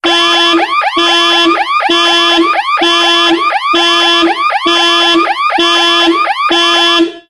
Car Alarm Device With Horn.mp3